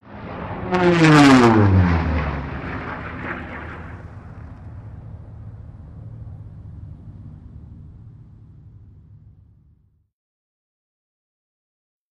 WW2 Fighters|P-38|Single
Airplane P-38 Pass By Fast Right To Left